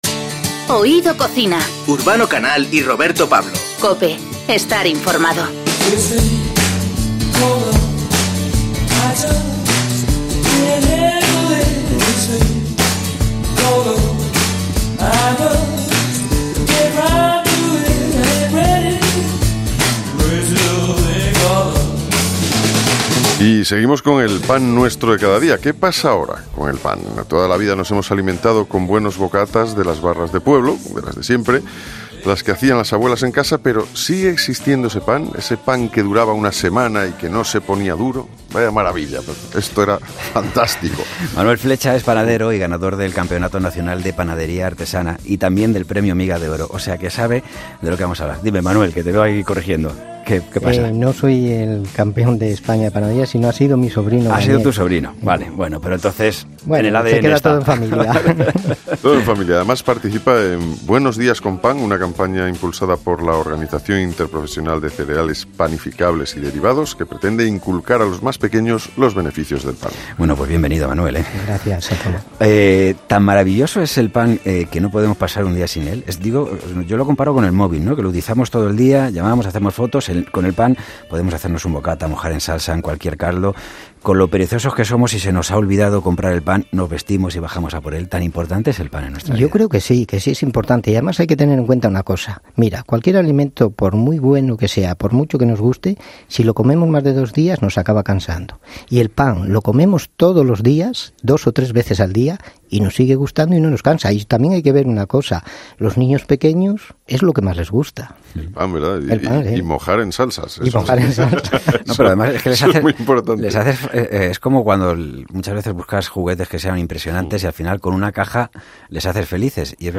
Te aseguro que es una charla como para mojar pan.